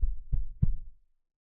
Minecraft Version Minecraft Version snapshot Latest Release | Latest Snapshot snapshot / assets / minecraft / sounds / block / creaking_heart / hurt / trail5.ogg Compare With Compare With Latest Release | Latest Snapshot